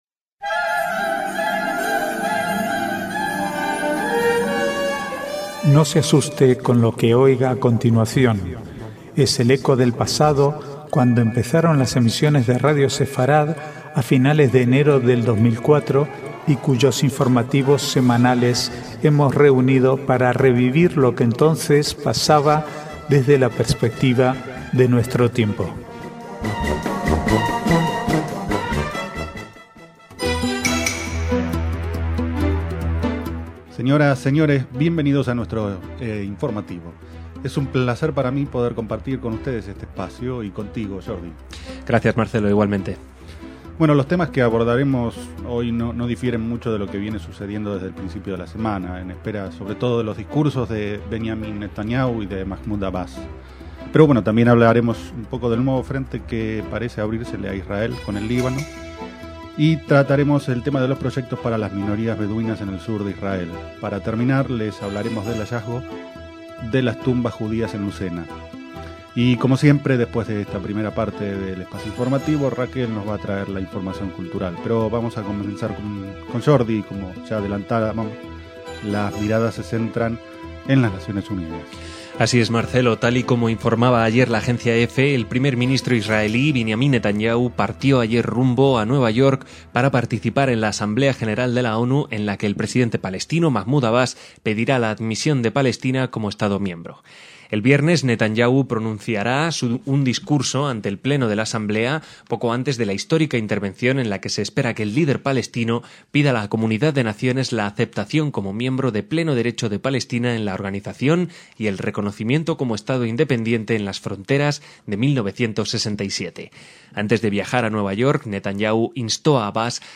Archivo de noticias del 21 al 27/9/2011